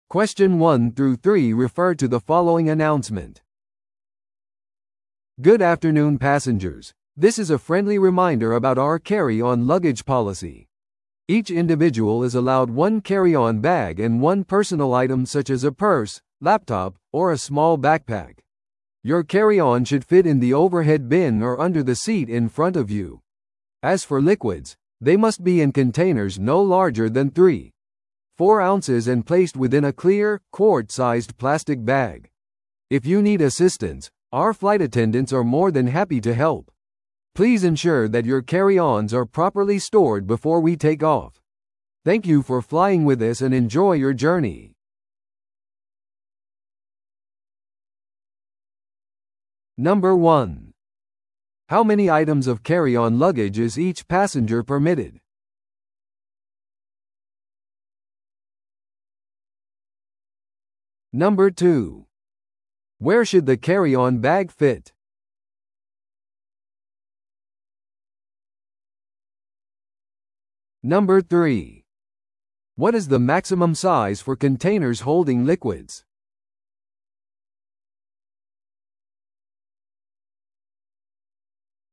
TOEICⓇ対策 Part 4｜機内での手荷物規則の案内 – 音声付き No.068